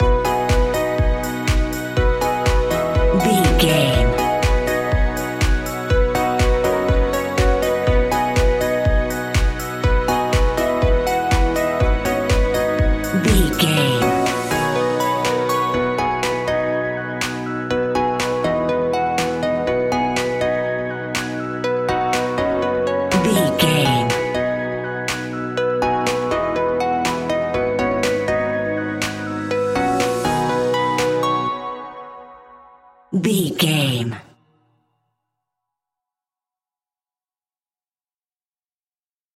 Ionian/Major
groovy
uplifting
driving
energetic
bouncy
synthesiser
drum machine
strings
electric piano
electronic
electronic instrumentals